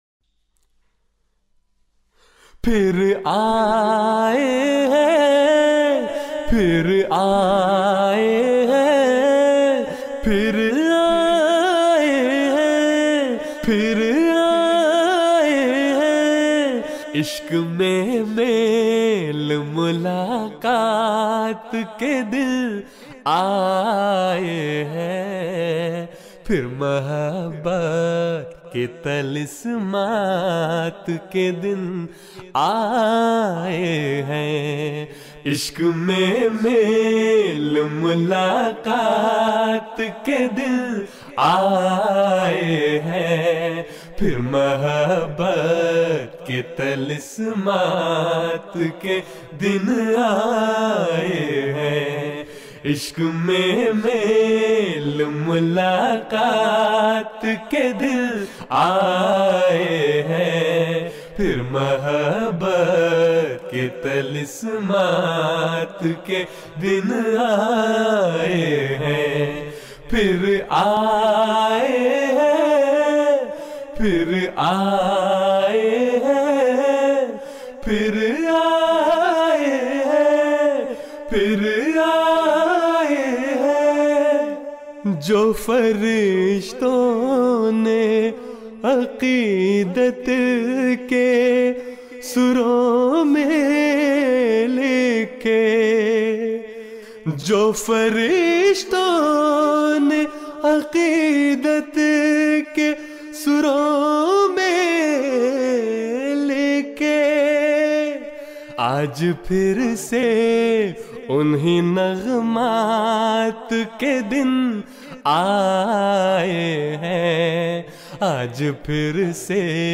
نظمیں (Urdu Poems)
جلسہ سالانہ یوکے ۲۰۱۷ء Jalsa Salana UK 2017